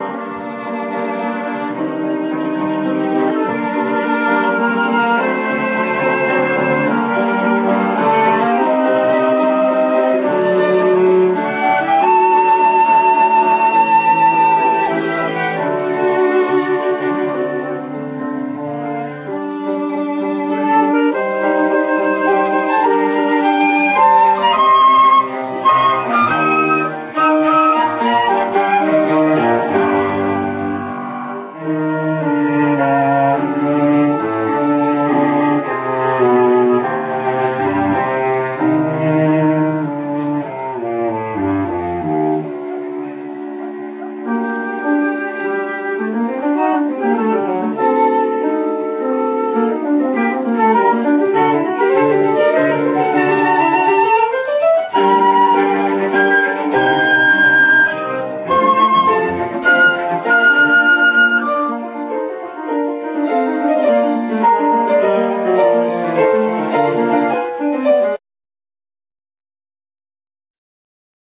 Concert
of the students of the master-class for piano
C.M. von Kleber: Trio in g-minor  Μουσική Δωματίου
Piano
Flute
Cello